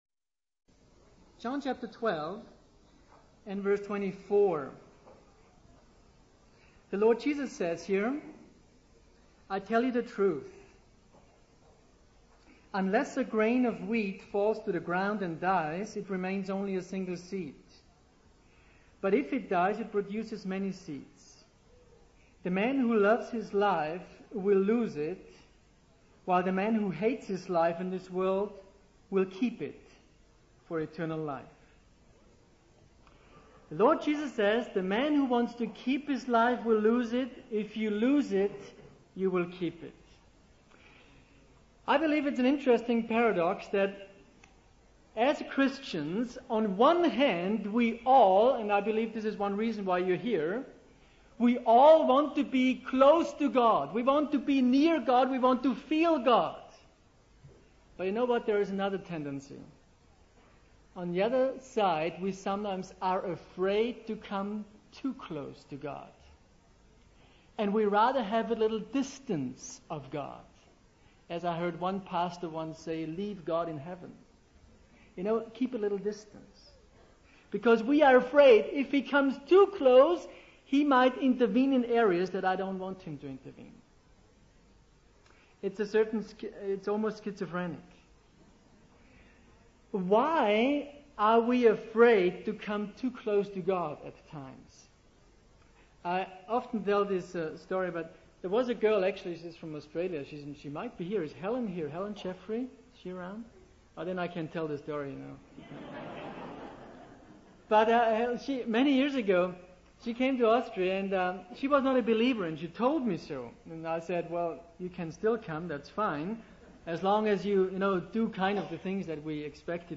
In this sermon, the preacher focuses on the verse John 12:24 where Jesus talks about the concept of dying to oneself in order to produce fruit.